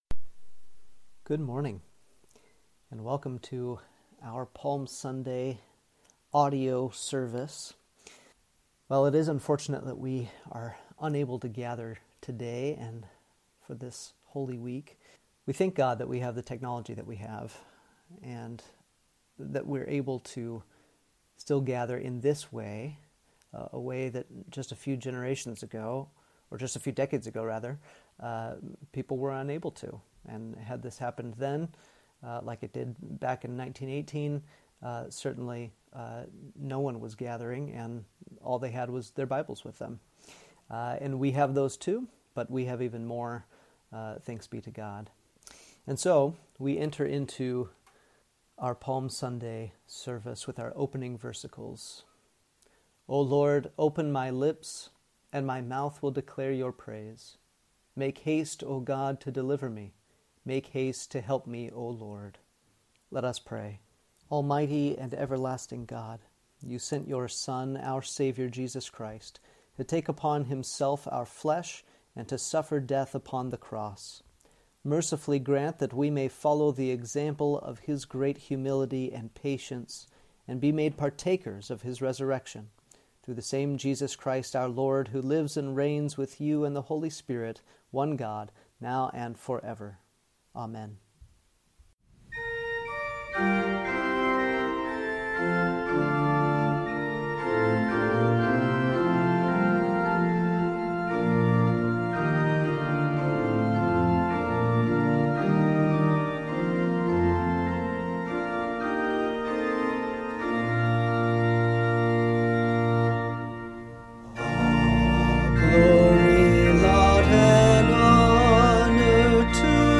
Service: Palm Sunday
palm-sunday-service.mp3